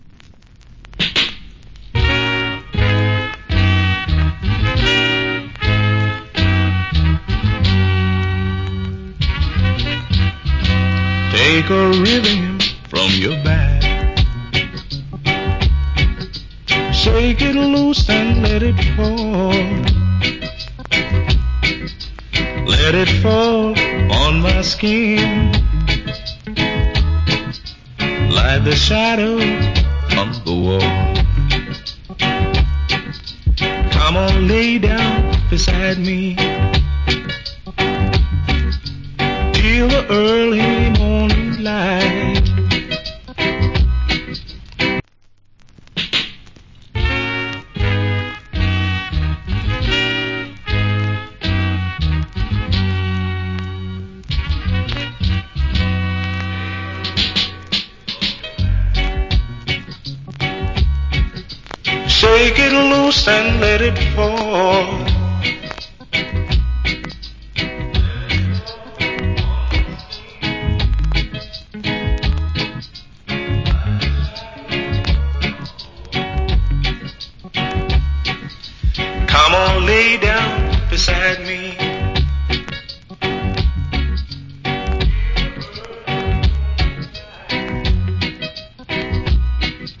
Reggae Vocal.